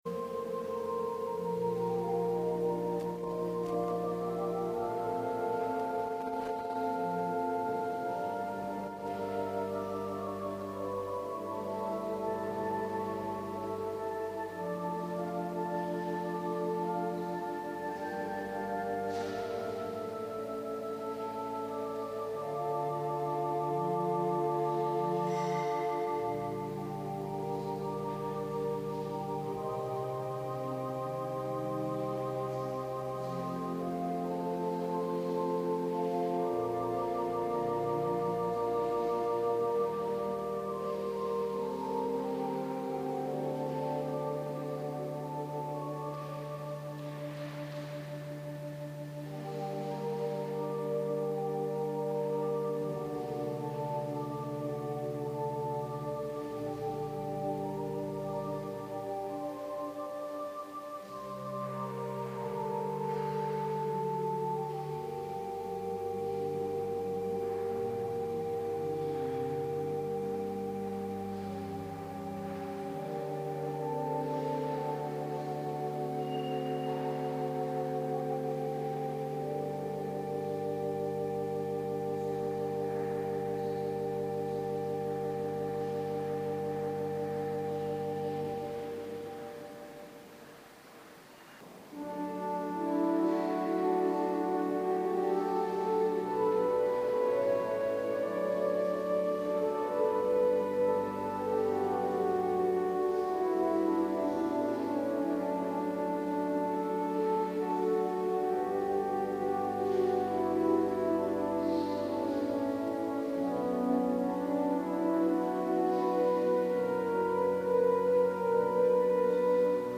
Preek Hoogfeest H. Drie-eenheid, jaar A, 18/19 juni 2011 | Hagenpreken
Lezingen